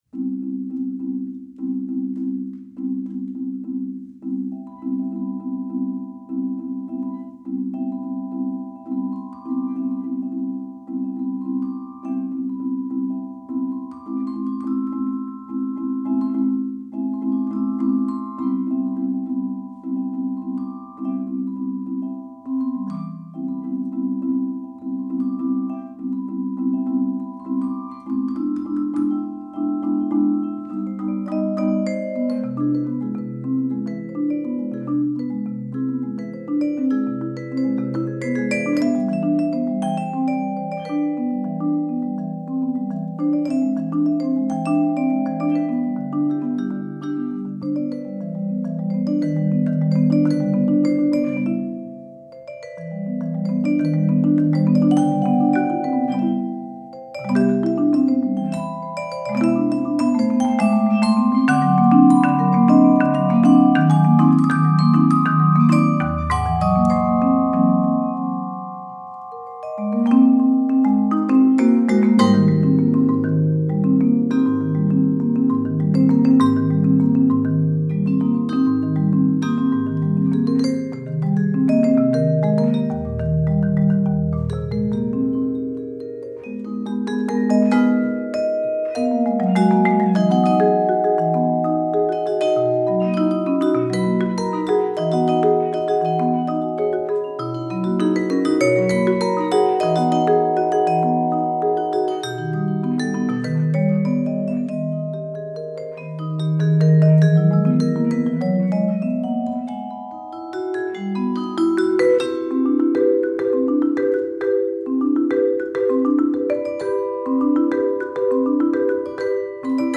Voicing: Mallet Duet